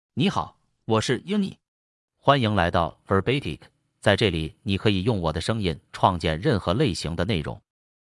Yunye — Male Chinese (Mandarin, Simplified) AI Voice | TTS, Voice Cloning & Video | Verbatik AI
Yunye is a male AI voice for Chinese (Mandarin, Simplified).
Voice sample
Listen to Yunye's male Chinese voice.
Male
Yunye delivers clear pronunciation with authentic Mandarin, Simplified Chinese intonation, making your content sound professionally produced.